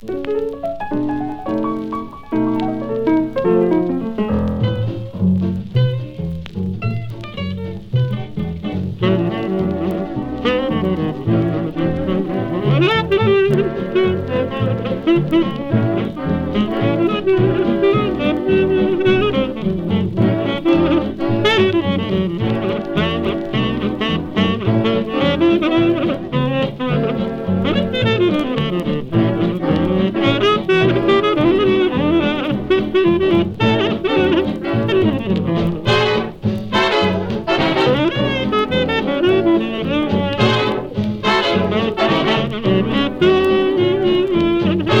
Jazz, Big Band, Swing　USA　12inchレコード　33rpm　Mono